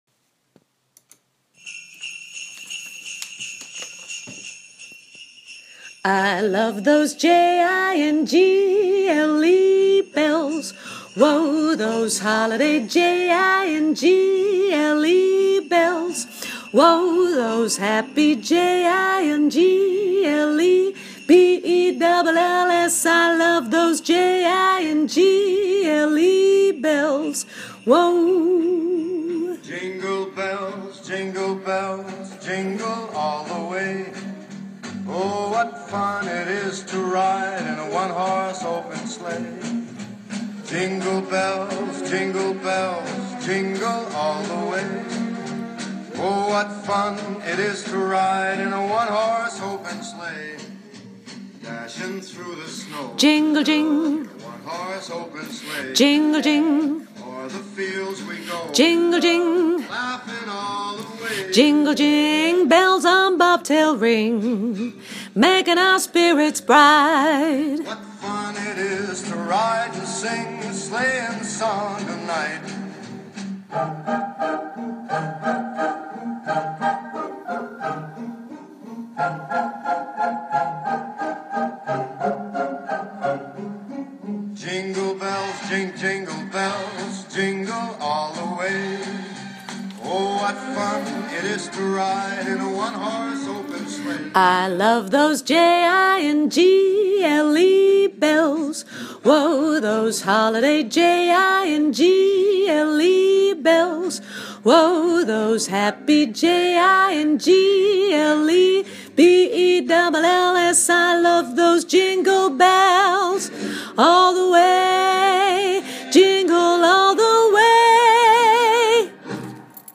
koortje alt